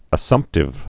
(ə-sŭmptĭv)